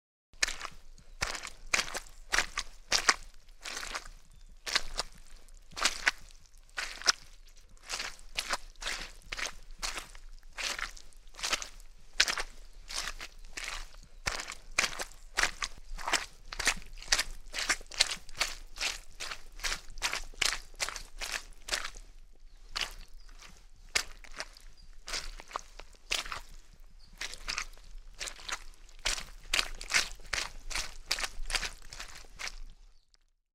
Tiếng Bước Chân đi trong Bùn đất nhão, ướt át….
Tiếng Bước chân đi trên Đất Bùn, Ẩm ướt… Tiếng Chó Vẩy tai, Lắc giũ rung mình… để loại bỏ nước, bụi bẩn…
Thể loại: Tiếng động
Description: Hiệu ứng âm thanh tiếng bước chân nhão nhoẹt trong bùn, Squishy Footsteps in Mud Sound Effects...
tieng-buoc-chan-di-trong-bun-dat-nhao-uot-at-www_tiengdong_com.mp3